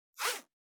408,ジッパー,チャックの音,洋服関係音,ジー,バリバリ,カチャ,ガチャ,シュッ,パチン,ギィ,カリ,カシャ,
ジッパー効果音洋服関係